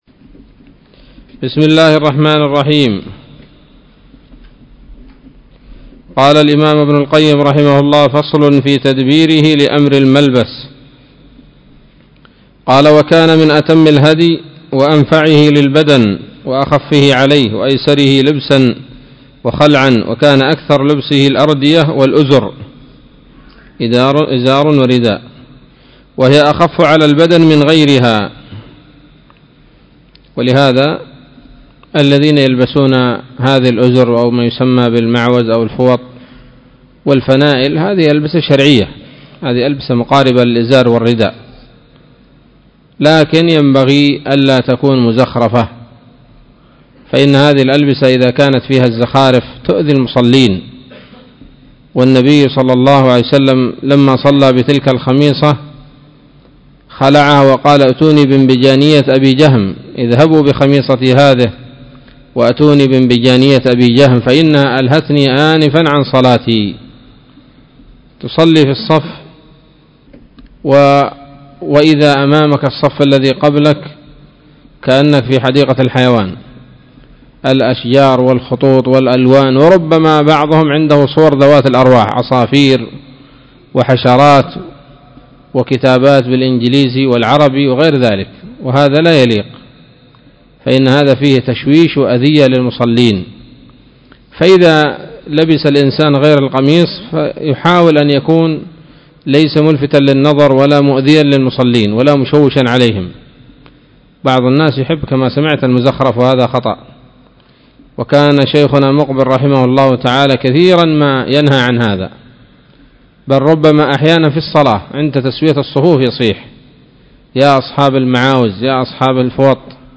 الدرس الخامس والستون من كتاب الطب النبوي لابن القيم